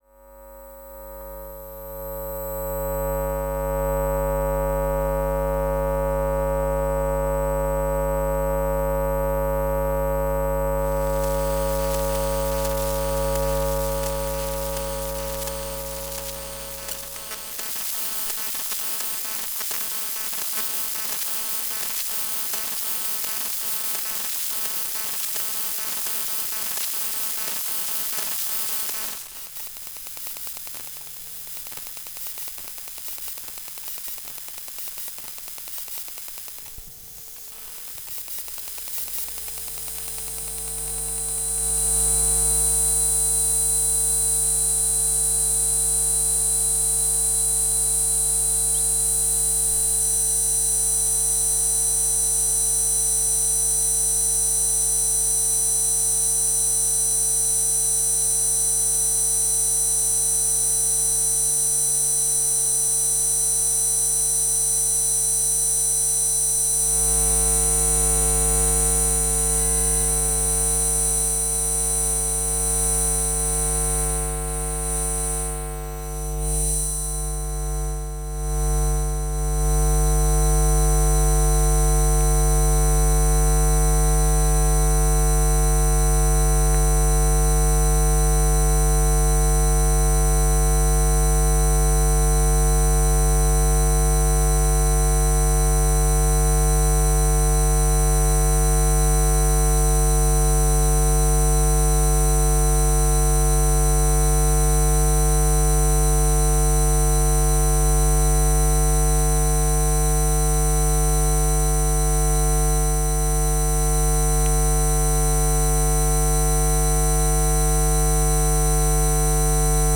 Occasionally I walk around town with my coil pick-up microphone.
sons-electromagnc3a8tics.wav